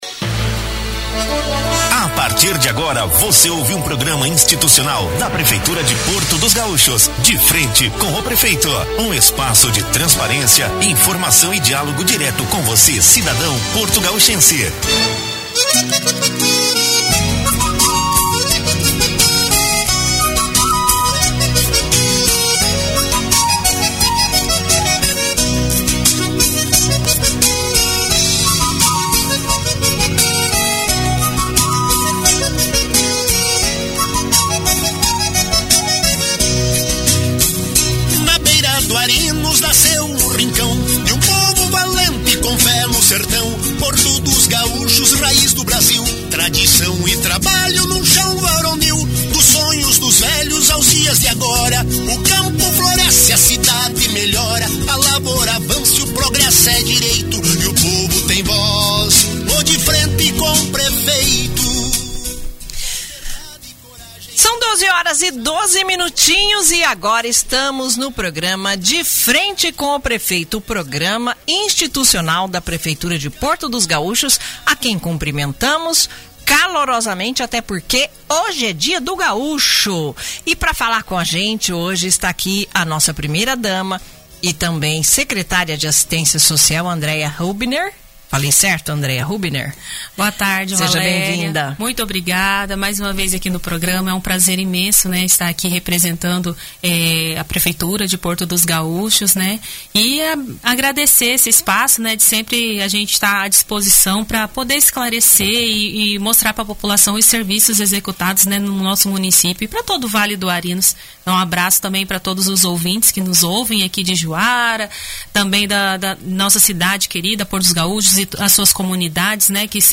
Prefeitura de Porto dos Gaúchos destaca obras, eventos culturais e ações sociais em programa institucional na Rádio Tucunaré
No programa “De Frente com o Prefeito”, transmitido no dia 19 de setembro, a Prefeitura de Porto dos Gaúchos apresentou um balanço das principais atividades em andamento no município. A entrevista contou com a participação da primeira-dama e secretária de Assistência Social, Andréia Hubiner, que destacou eventos culturais, campanhas de conscientização e o andamento de obras estruturais no município